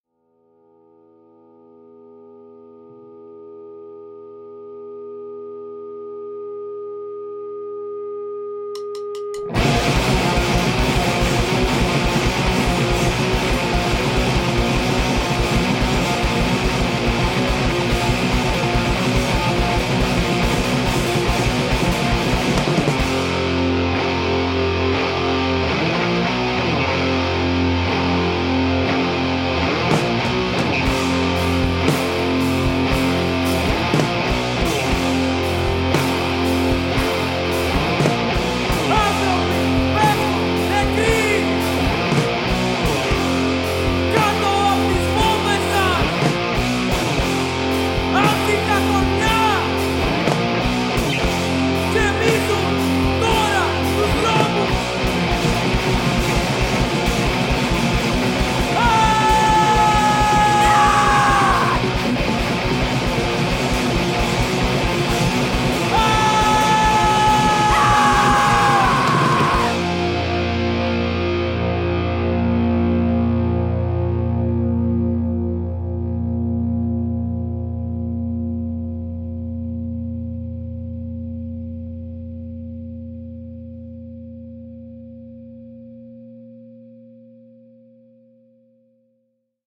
DIY Hardcore Punk band from Ioannina, Greece
κιθάρα-φωνητικά
drums-φωνητικά